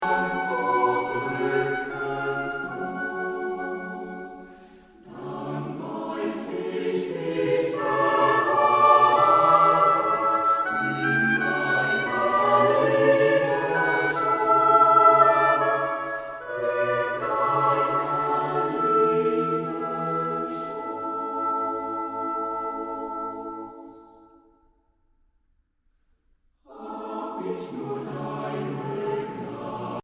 Trostvoll, harmonisch und warm